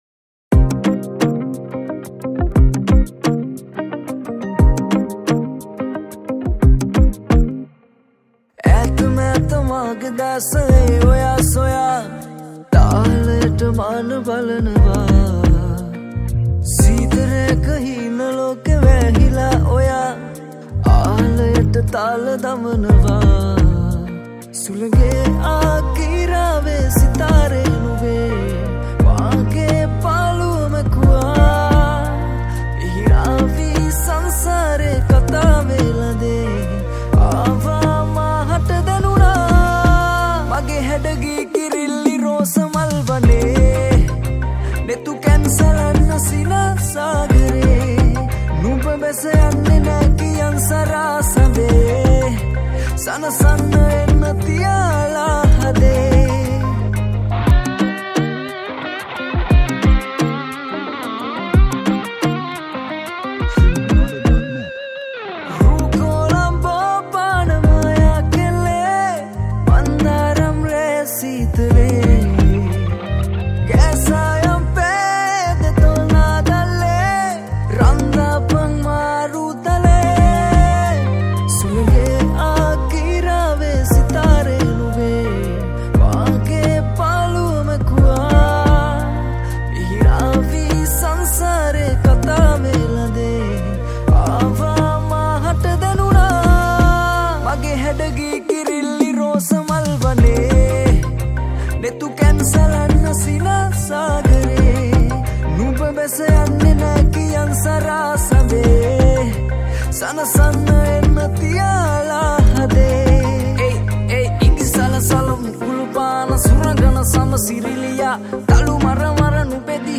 Synth and Piano
All guitars